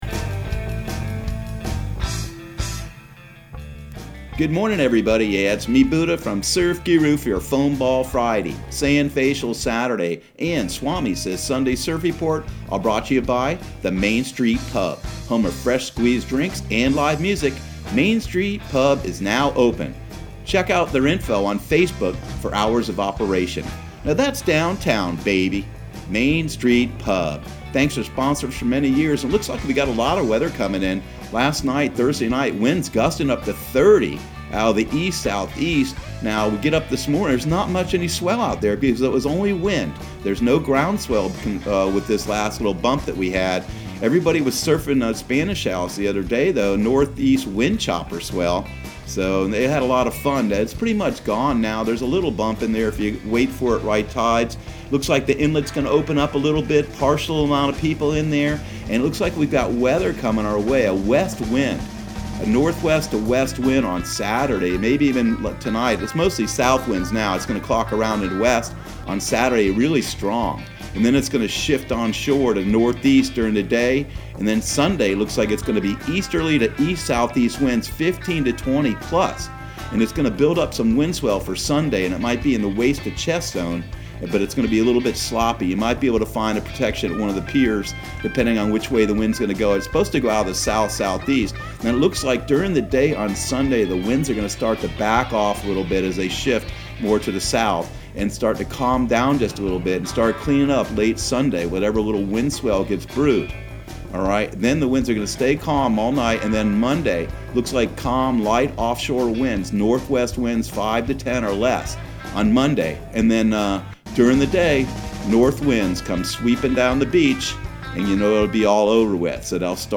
Surf Guru Surf Report and Forecast 05/08/2020 Audio surf report and surf forecast on May 08 for Central Florida and the Southeast.